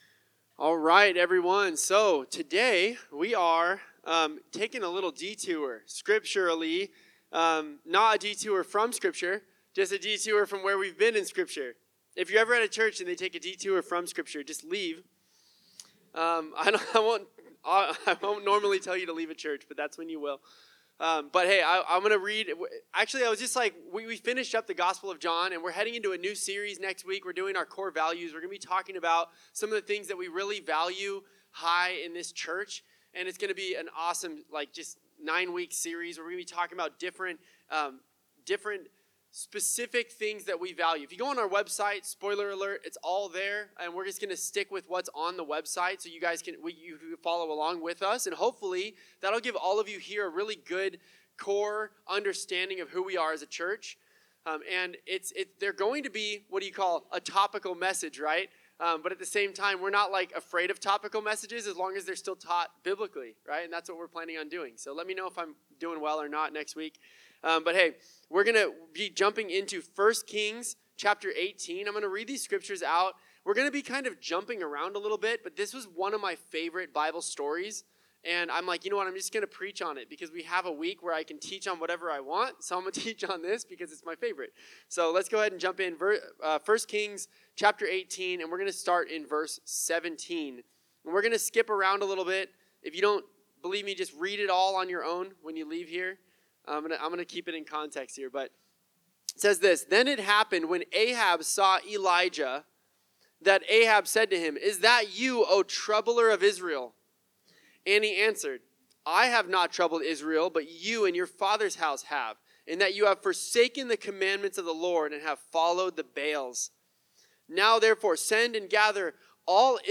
Sermons | Revive Church